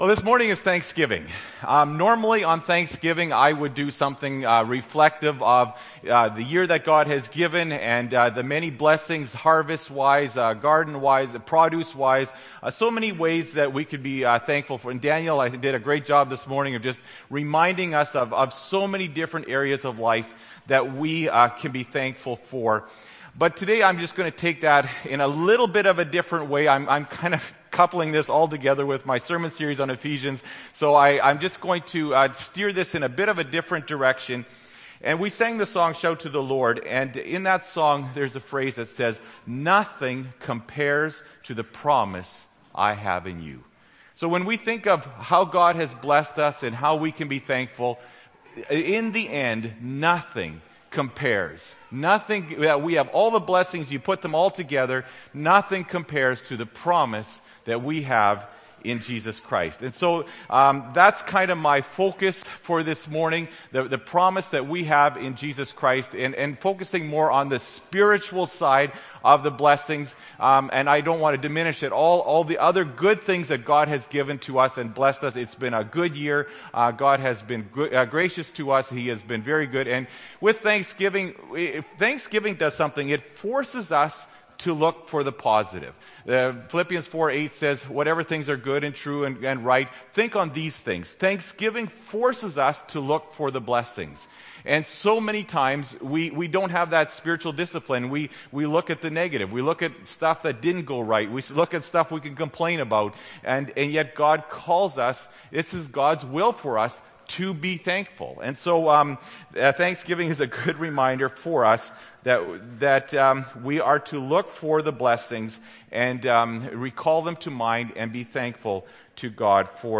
Oct. 20, 2013 – Sermon